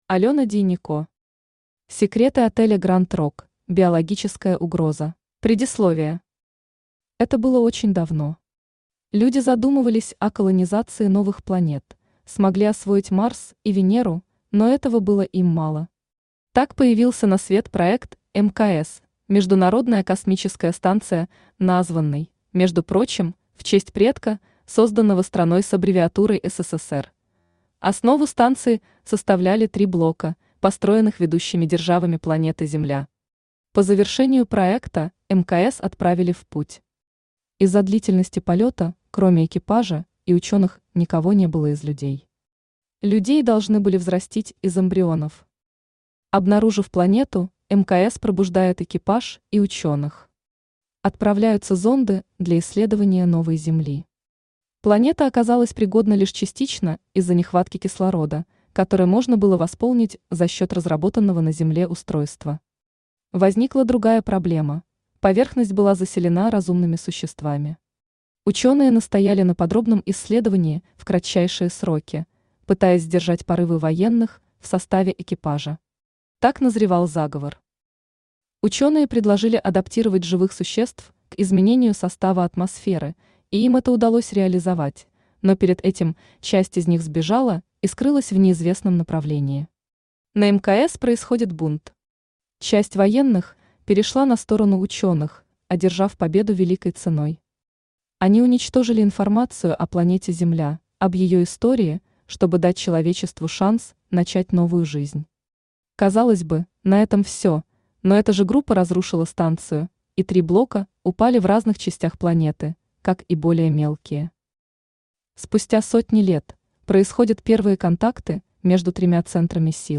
Читает: Авточтец ЛитРес
Аудиокнига «Секреты отеля Гранд-Рок: Биологическая угроза».